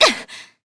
Miruru_L-Vox_Damage_jp_01.wav